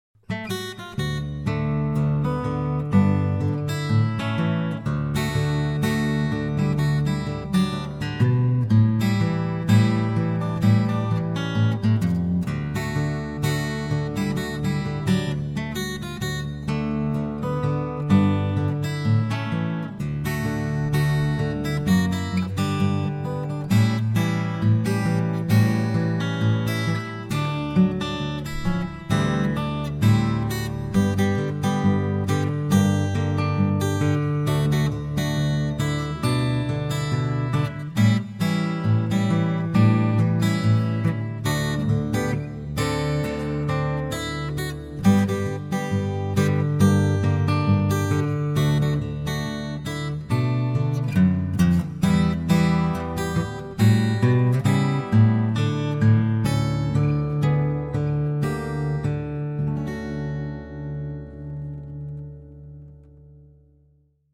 Arrangiert für Fingerstyle-Gitarre (Buch & CD)